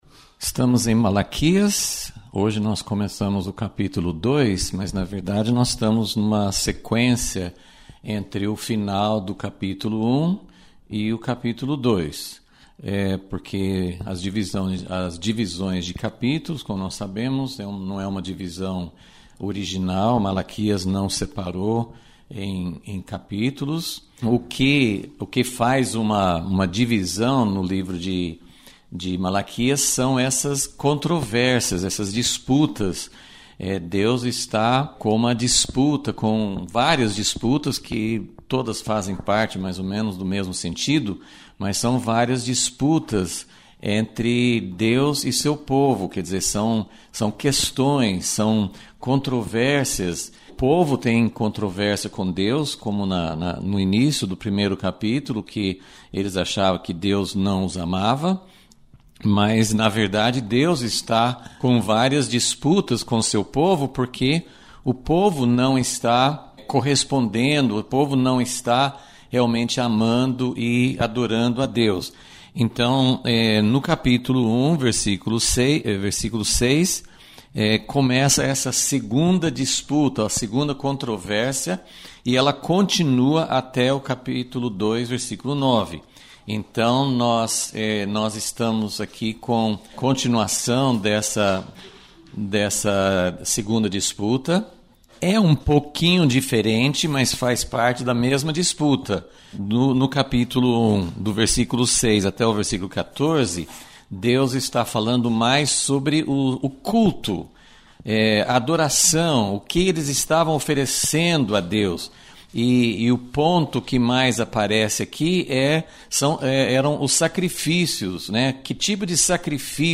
Aula 6 – Vol.37 – A controvérsia de Deus com os Sacerdotes